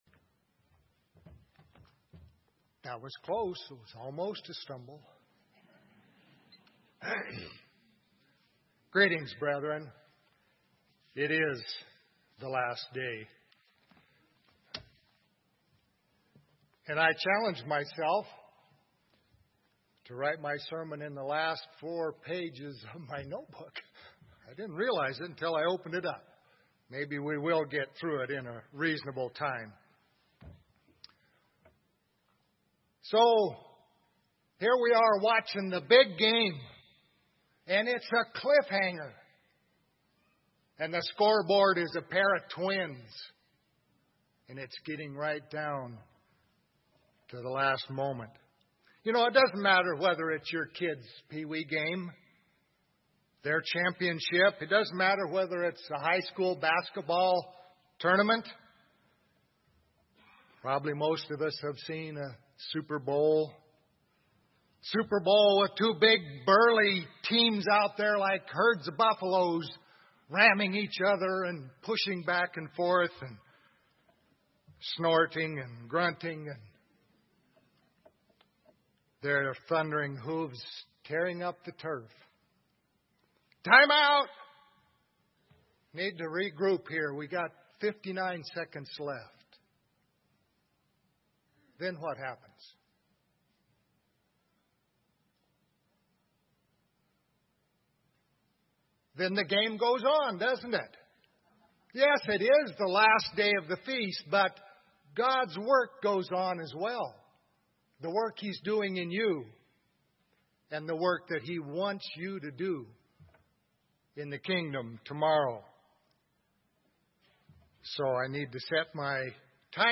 This sermon was given at the Bend-Redmond, Oregon 2017 Feast site.